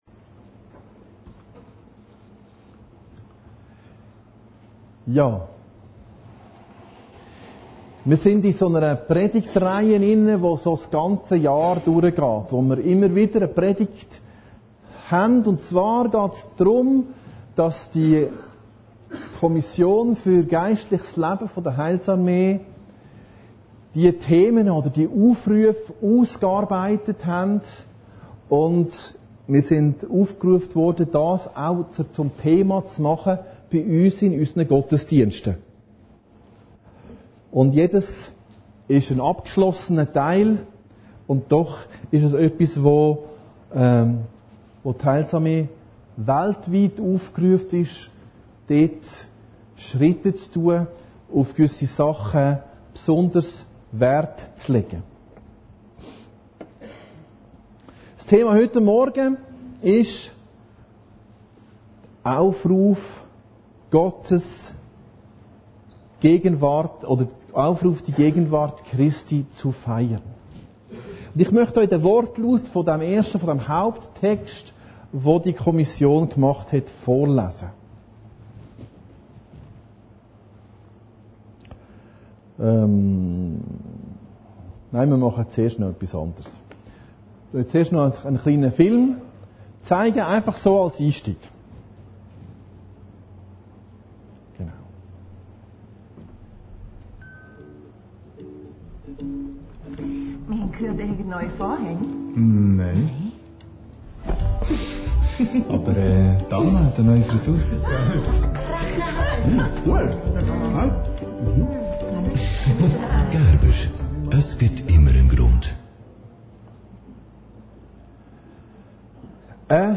Predigten Heilsarmee Aargau Süd – Aufruf die Gegenwart Christi zu feiern